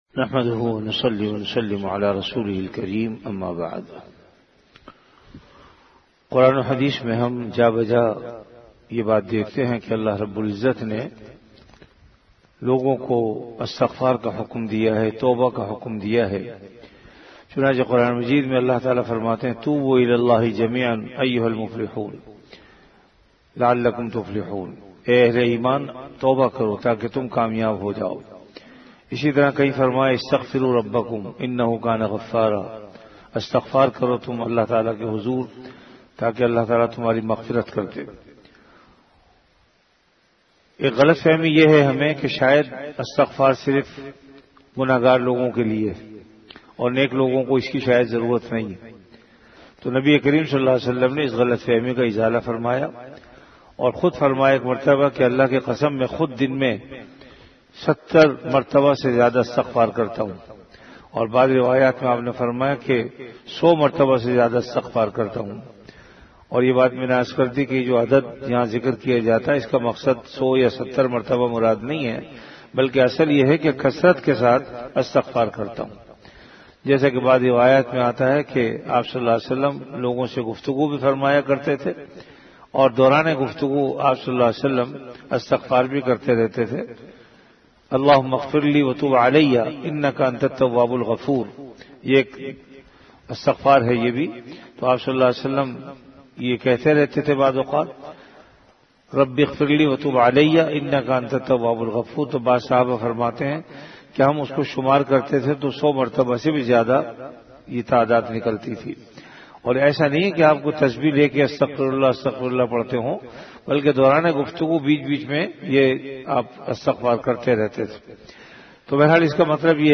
CategoryRamadan - Dars-e-Hadees
VenueJamia Masjid Bait-ul-Mukkaram, Karachi
Event / TimeAfter Fajr Prayer